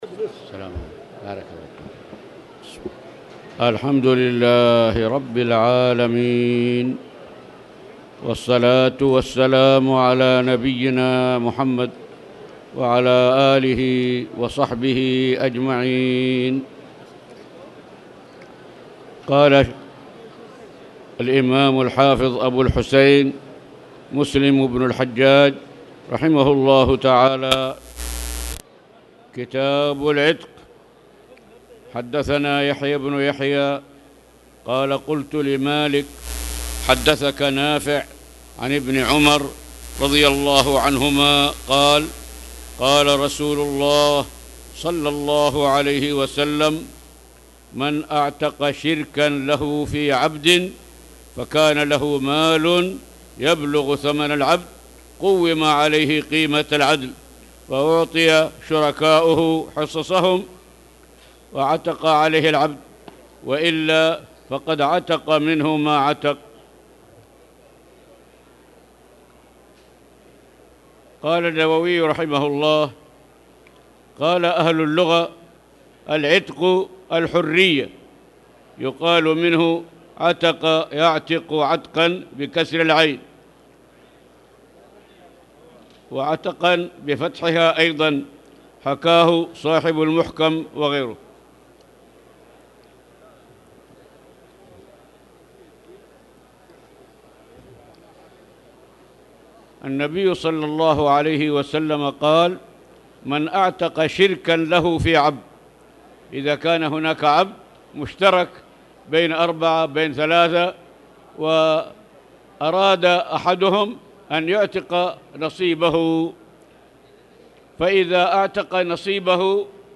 تاريخ النشر ٤ ربيع الثاني ١٤٣٨ هـ المكان: المسجد الحرام الشيخ